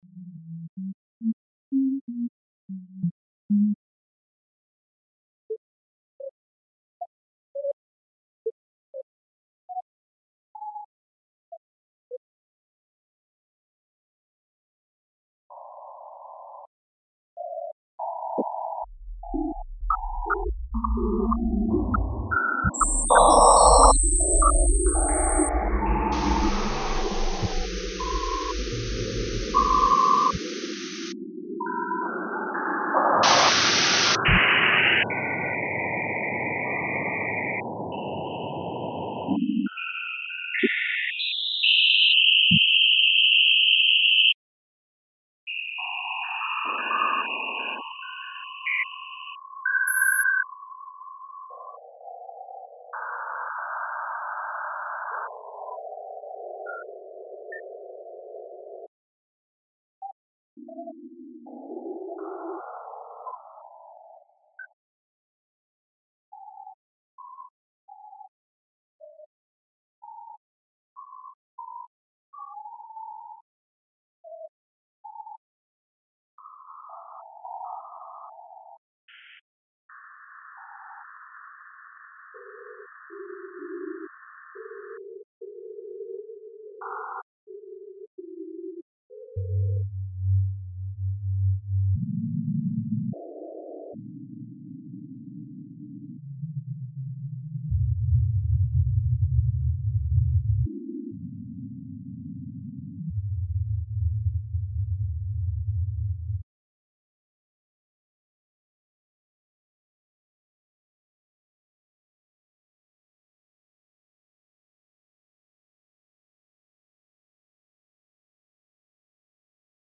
serial tape compositions (1979-82)